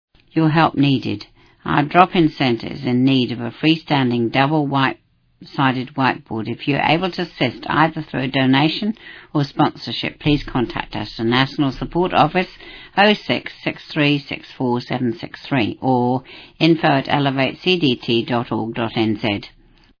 Genre: Speech..Released: 2016.